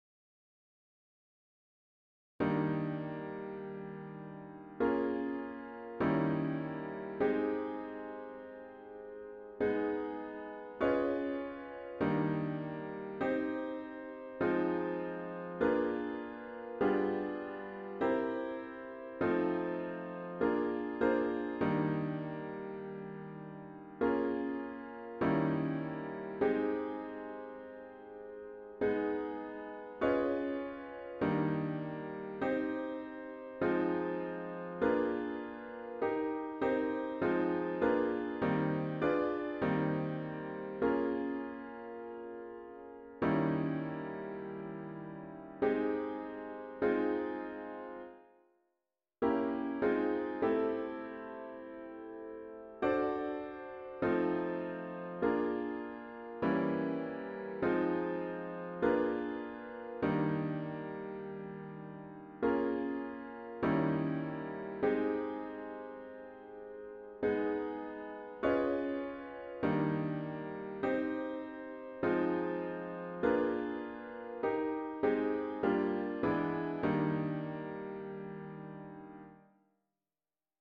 An audio of the chords only version is